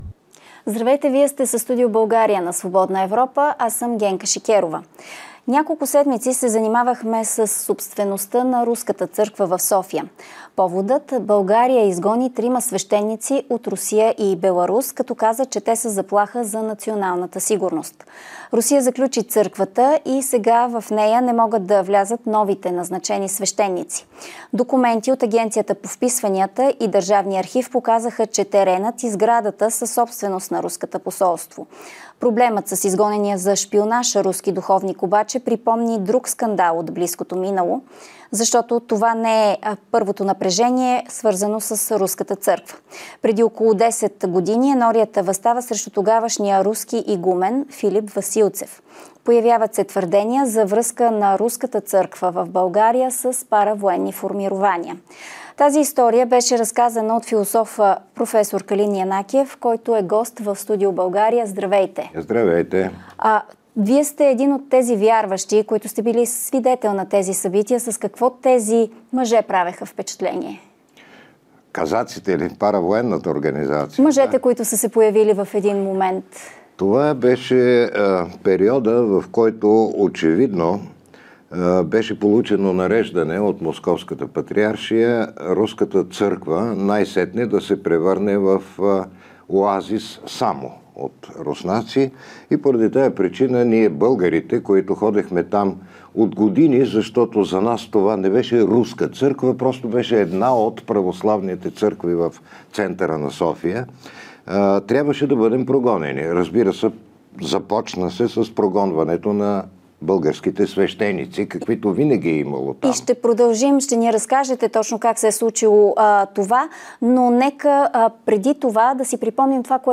Проф. Калин Янакиев отговаря на въпросите, които тази ситуация повдига - защо Русия контролира достъпа до църква в центъра на столицата и използва ли я за други цели, не само църковни.